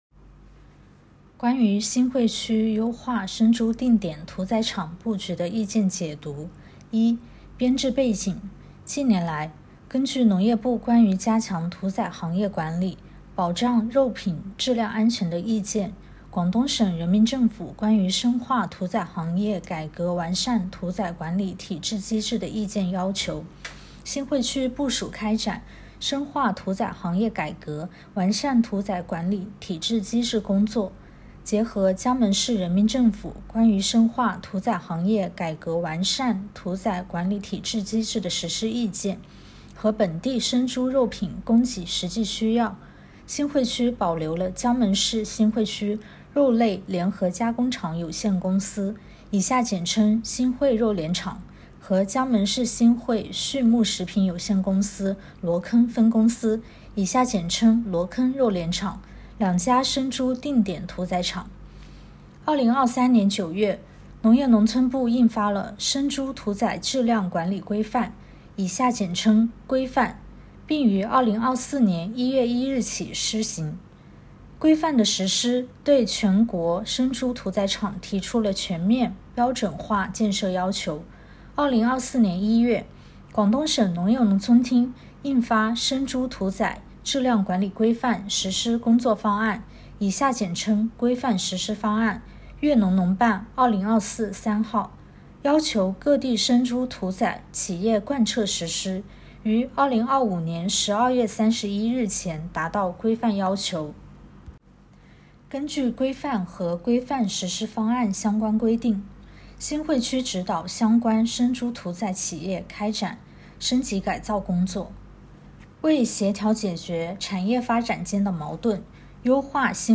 音频解读：关于印发《关于新会区优化生猪定点屠宰厂(场)布局的意见》的通知